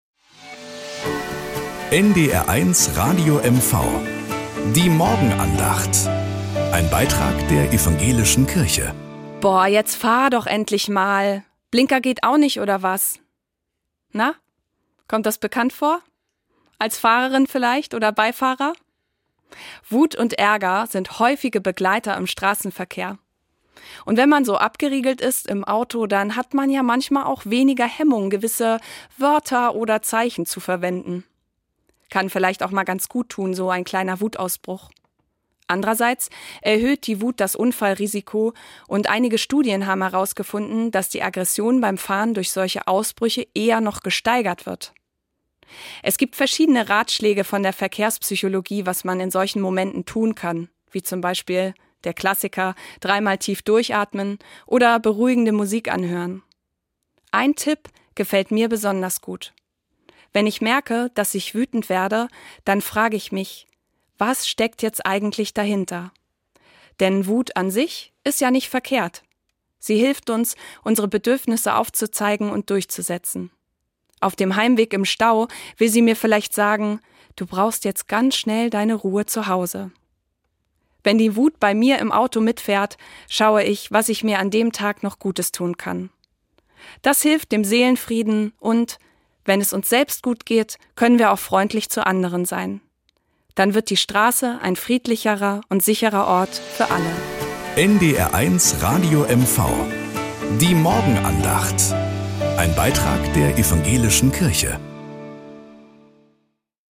Morgenandacht auf NDR 1 Radio MV